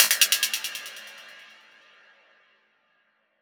/audio/sounds/Extra Packs/Dubstep Sample Pack/FX/